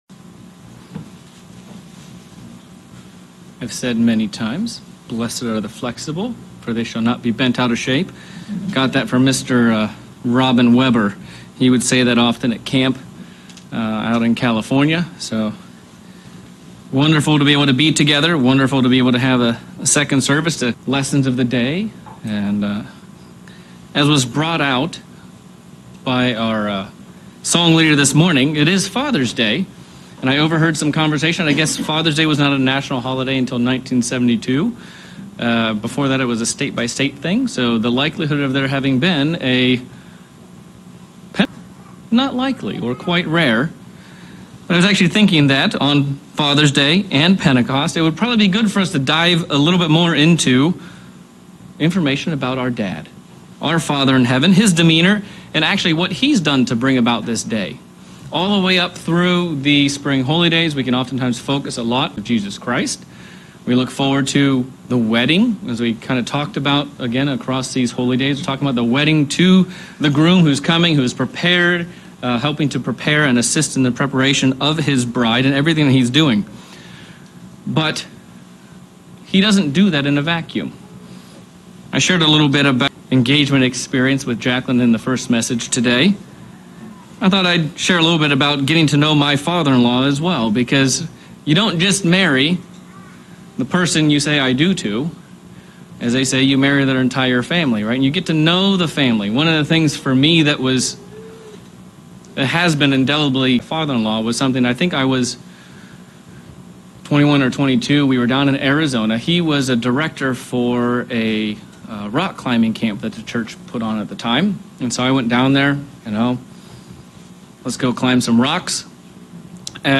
Pentecost PM sermon looking at the qualities the Father is looking for in as a bride for his son